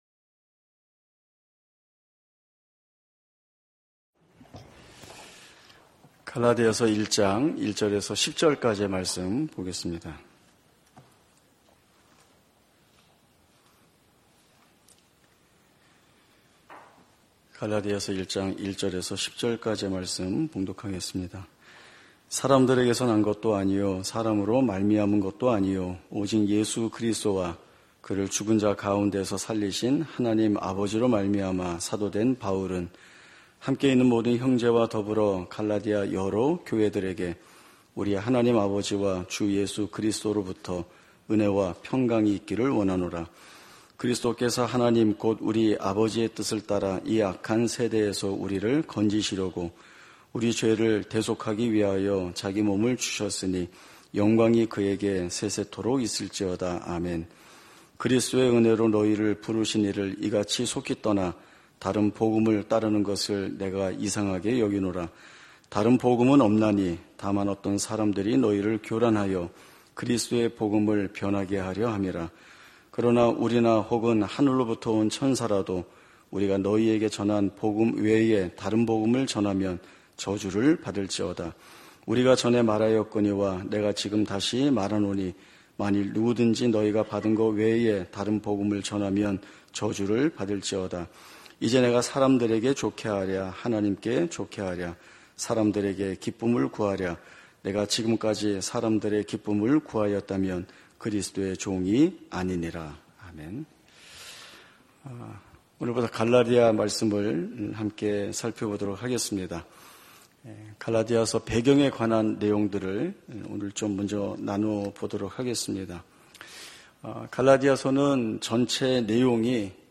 예배 수요예배